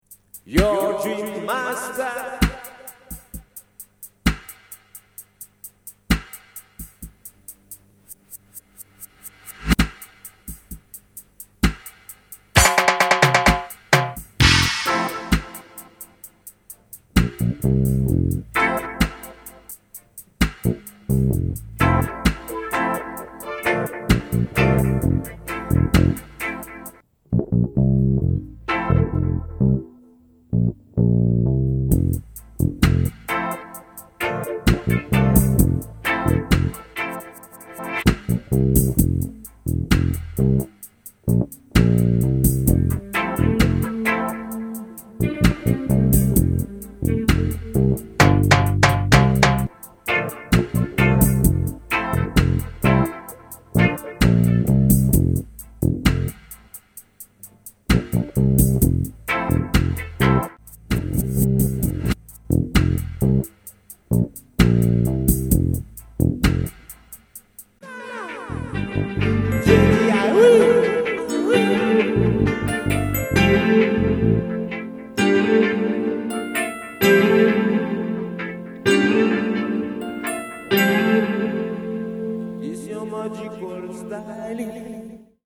REGGAE DUB SECTION